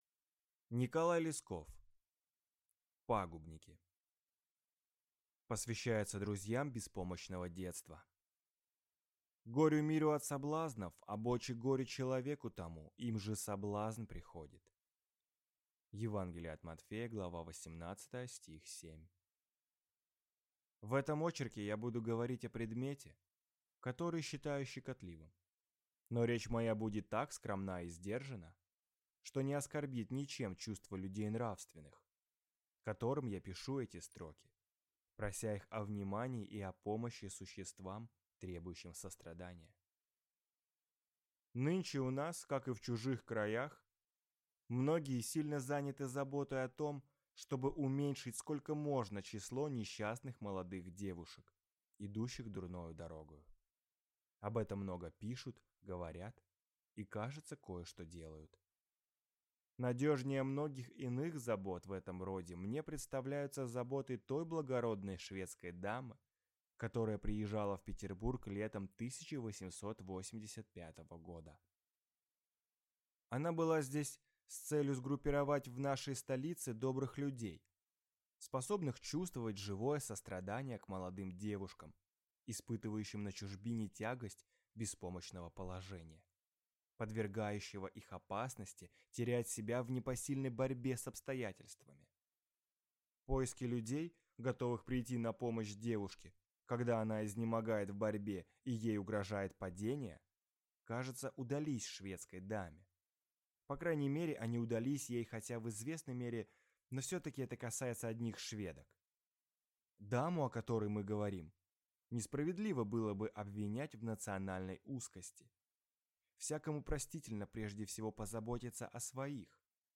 Аудиокнига Пагубники | Библиотека аудиокниг